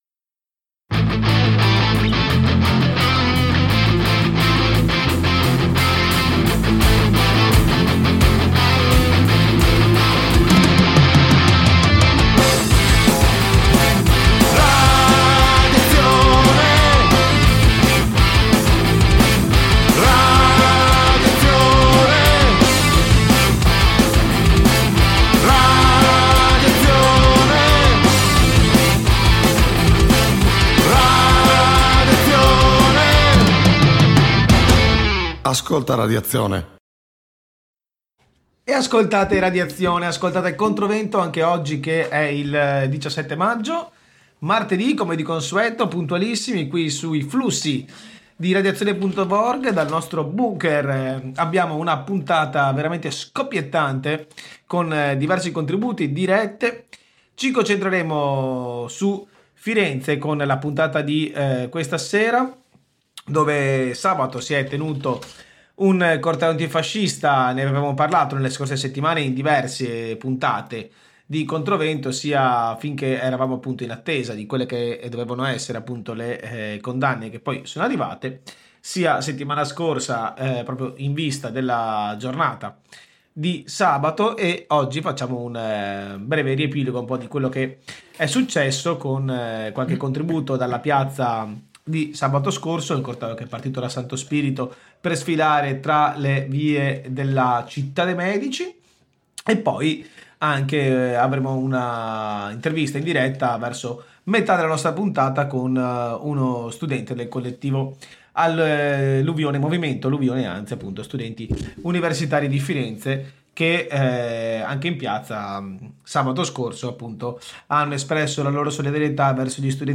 Puntata di ControVento all’insegna della solidarietà di fronte alla repressione: contributo dal corteo di Firenze Antifascista sulle condanne a 9 antifascisti per gli scontri alle Piagge del 2014 quando FN fu cacciata a calci dalle strade della città. Un secondo contributo registrato al CPA Fi Sud da un compagno del collettivo d’Ateneo sulla sulla situazione all’università e sui vari procedimenti in corso.